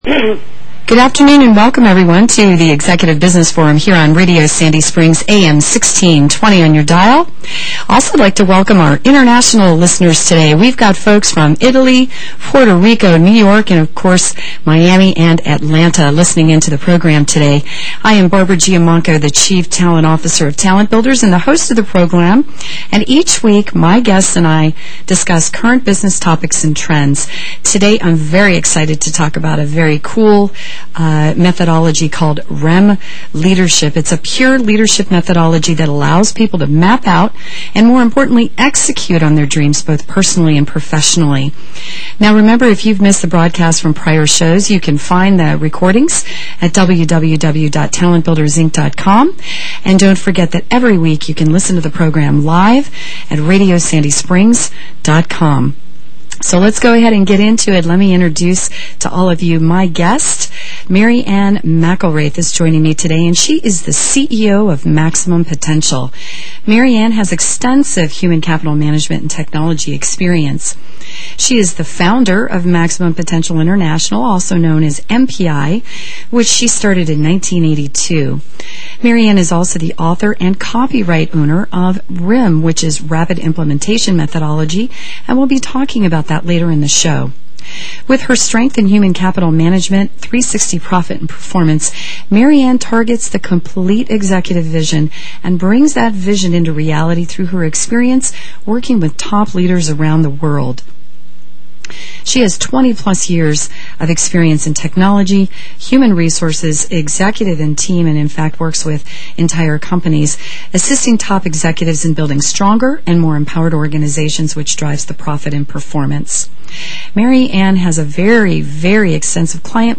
Radio podcast interview